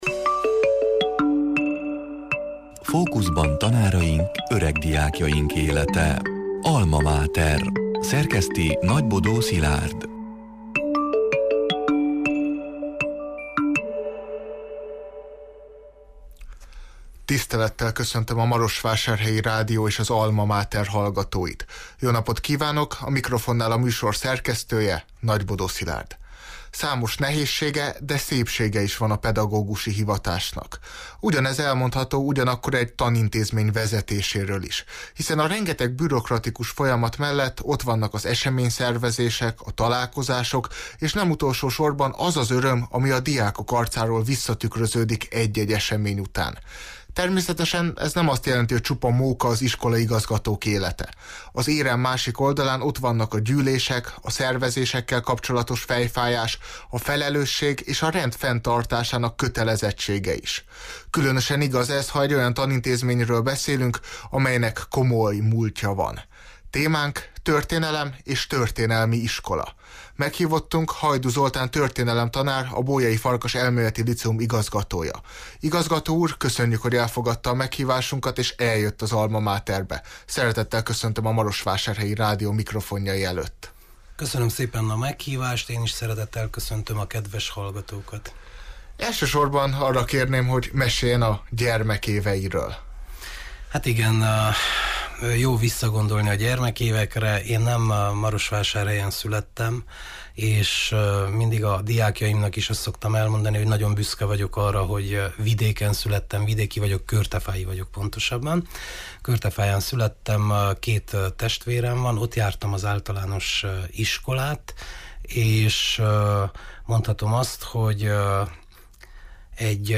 (elhangzott: 2023. január 27-én, péntek délután hat órától élőben)